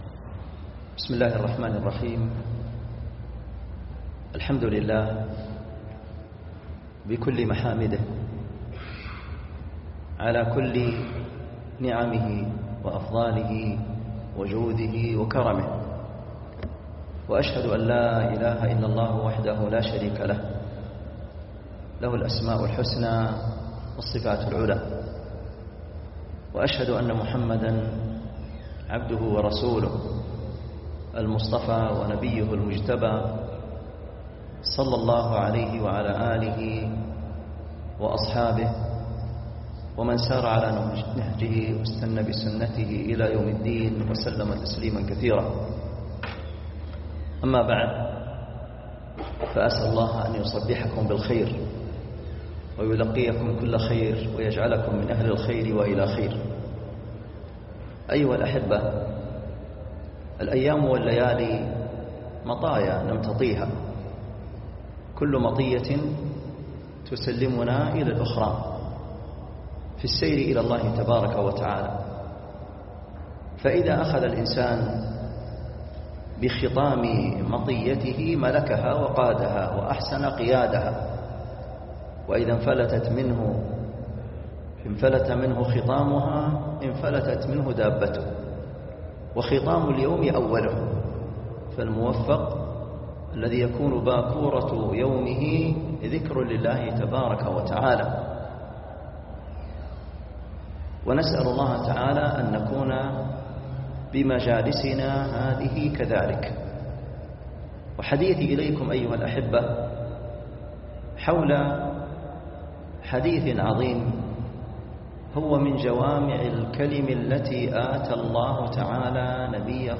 محاضرة
جامع الرحمن - حي الصفا